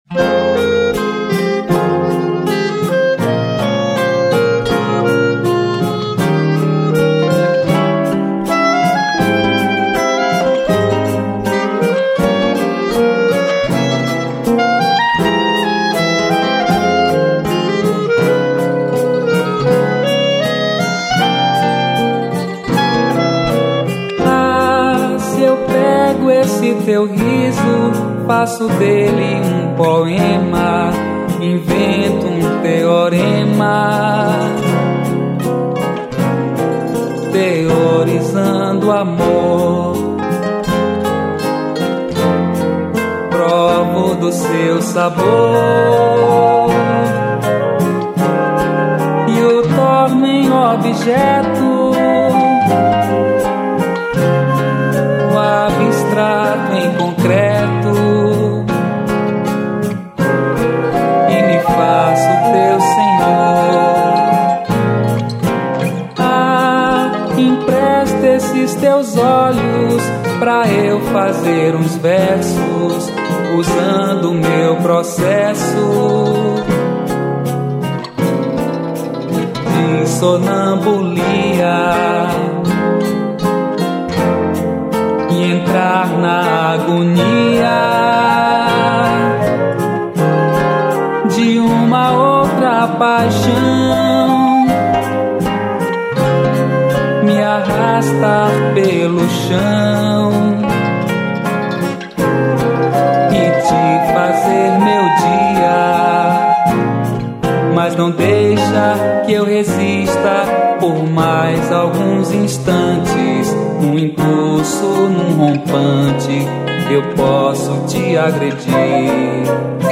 Cavaquinho